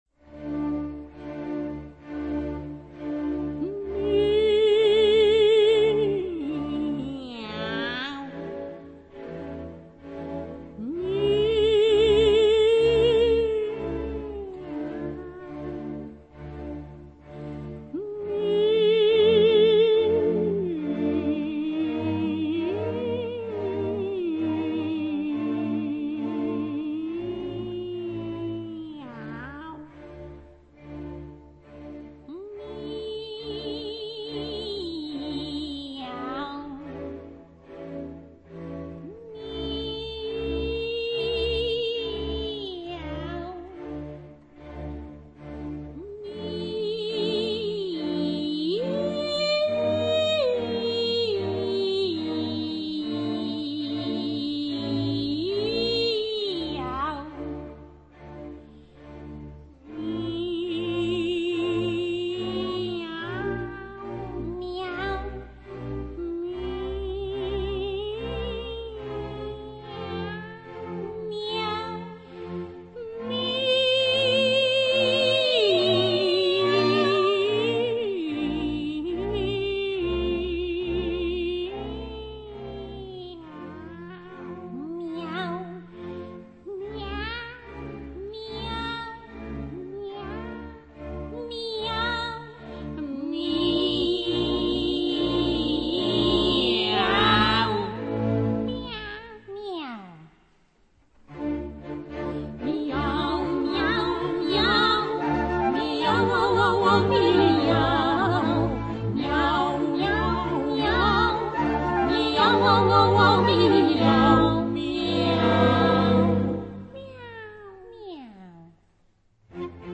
У меня "завалялся" ещё один дуэт.